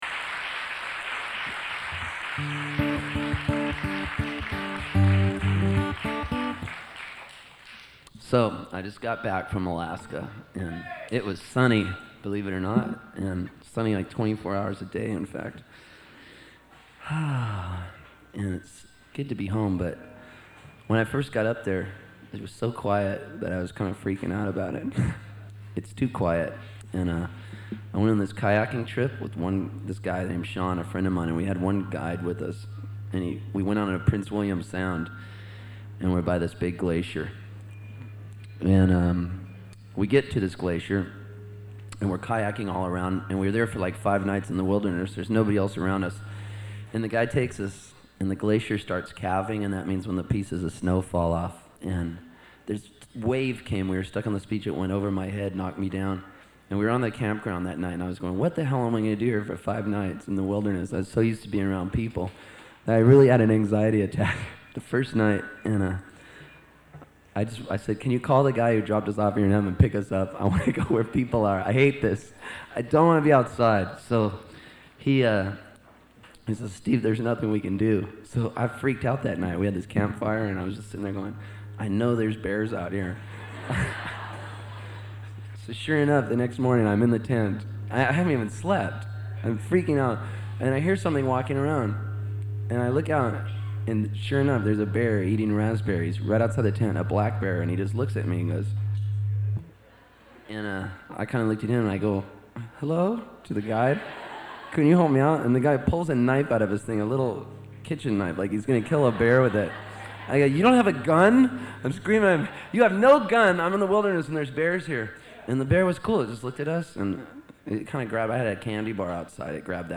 Index of /audio/Steve_Poltz/Java_Joe's_-_1997-07-26
03_-_Camping_In_Alaska_Story.mp3